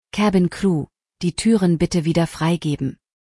DisarmDoors.ogg